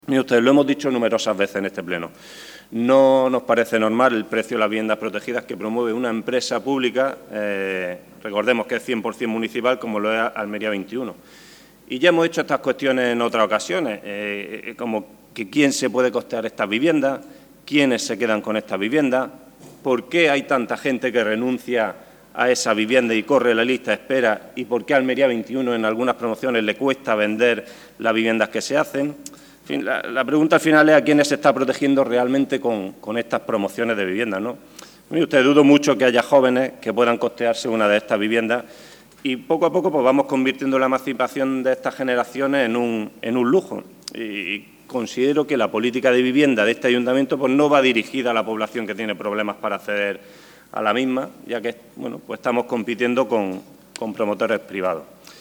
El PSOE ha votado a favor de ese punto del orden del día que ha defendido el viceportavoz, Raúl Enríquez, porque respalda cualquier iniciativa que contribuya a  construir más vivienda protegida en nuestra ciudad, si bien ha afeado al equipo de Gobierno del PP que las viviendas públicas del Ayuntamiento estén costando más de 200.000 euros.